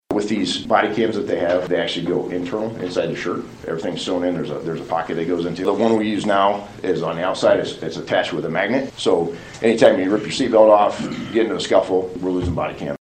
LaGrange County Sheriff Tracy Harker made a request to the LaGrange County Council Monday about some new body and vehicle cameras he would like to get for his department. Harker says one of the important factors about the body cams is that they would be better secured to the officers than the current ones.